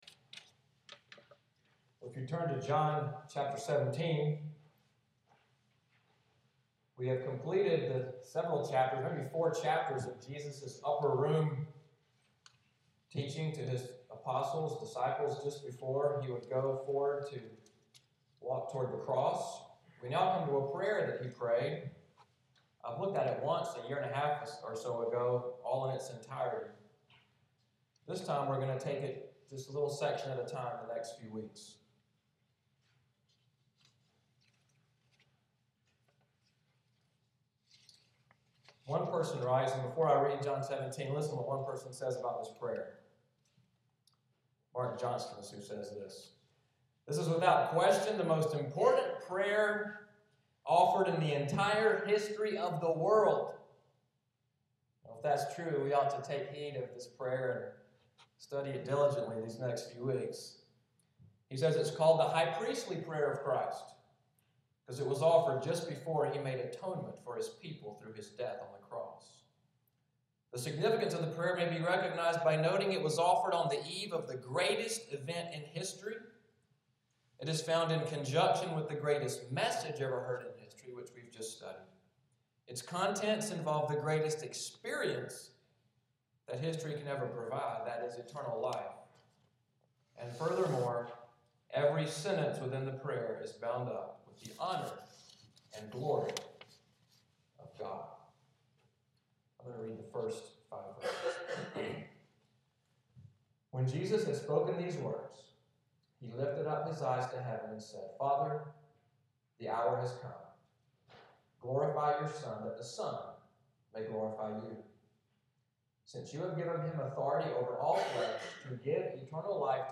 Audio from the sermon, “A Glorious Prayer,” Sunday January 19, 2014